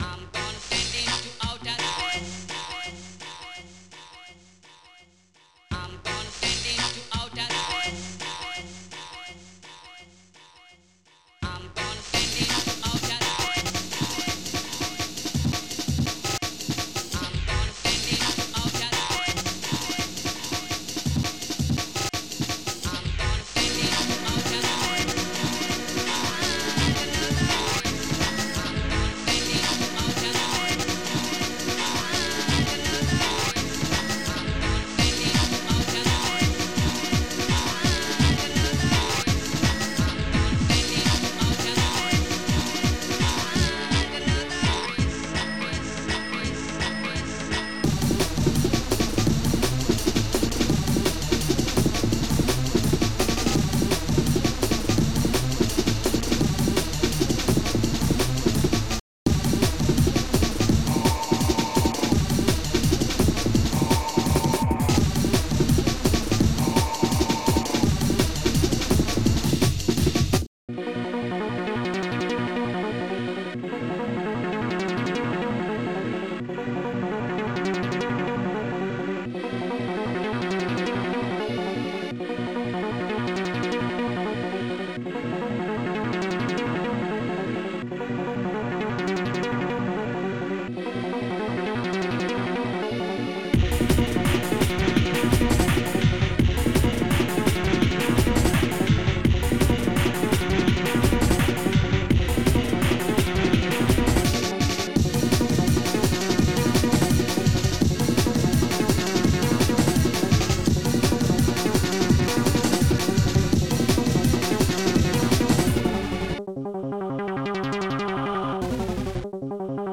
Protracker and family